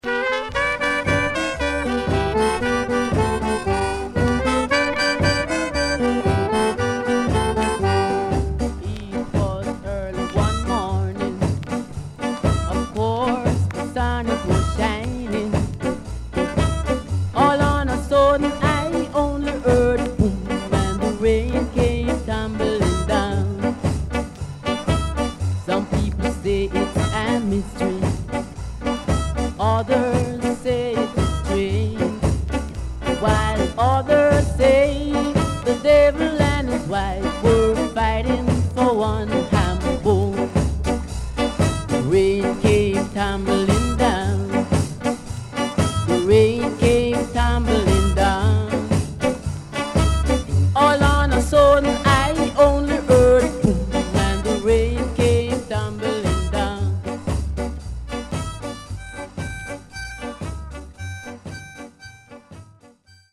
HOME > Back Order [VINTAGE 7inch]  >  SKA
SIDE A:少しチリノイズ、プチノイズ入ります。